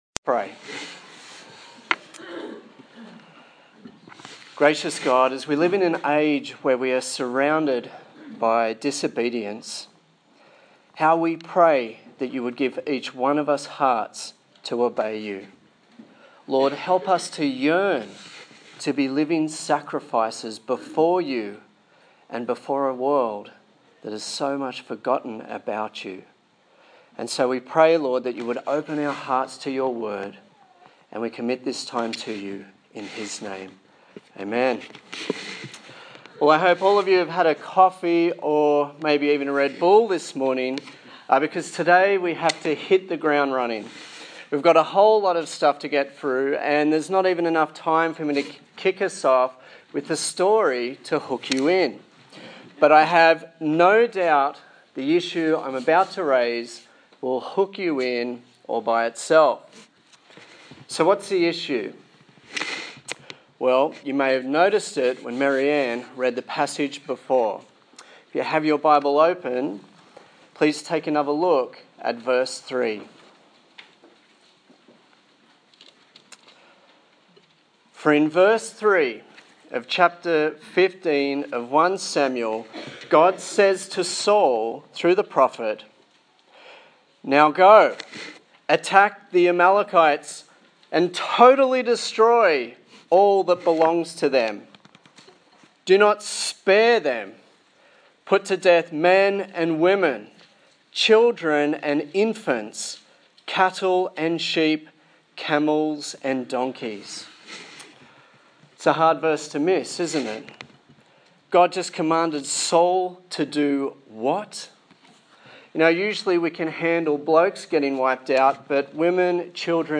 1 Samuel Passage: 1 Samuel 15 Service Type: Sunday Morning